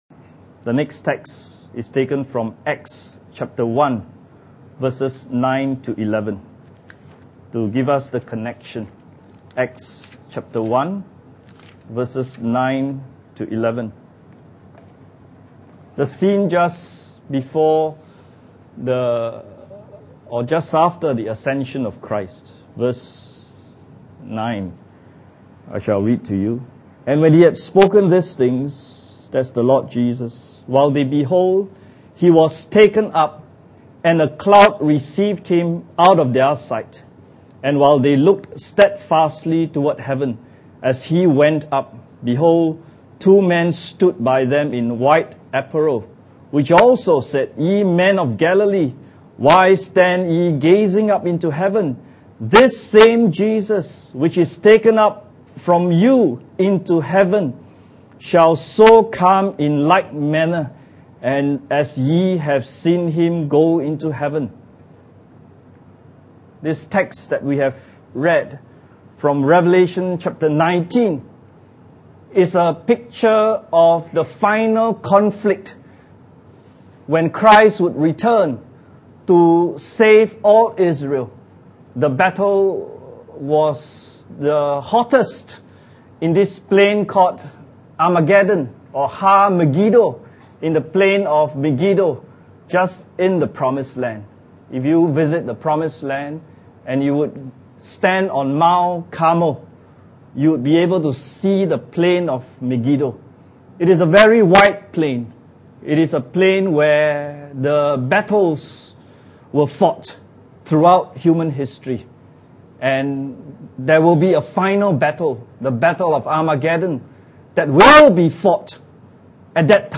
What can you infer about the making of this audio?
Church Camp 2015 Looking for That Blessed Hope – Return (History’s Final War) Message 5